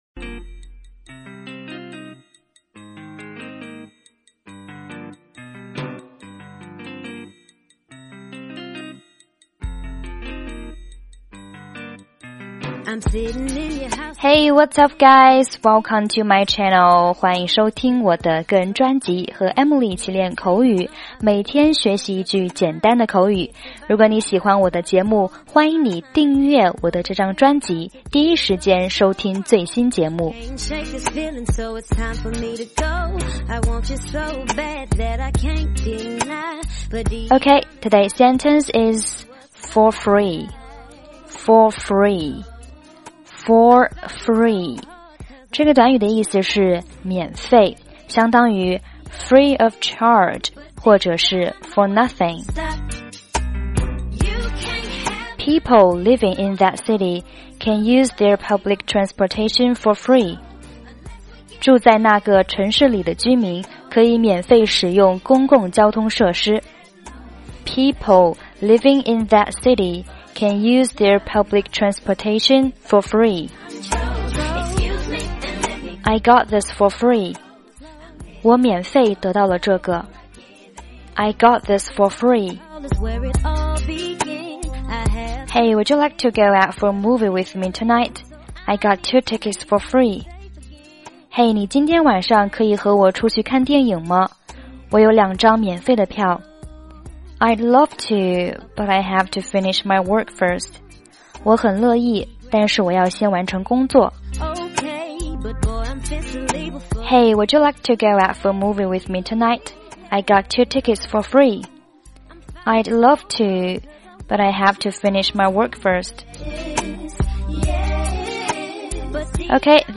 背景音乐：